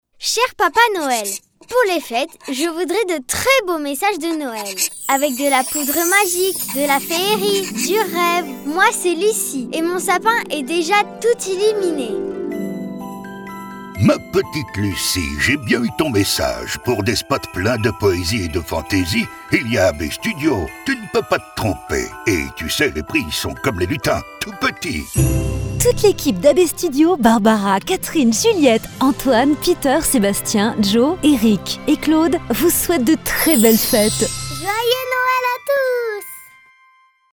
Toute l’équipe d’AB Studio vous souhaite de très belles fêtes à travers un spot de Noël.